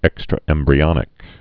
(ĕkstrə-ĕmbrē-ŏnĭk)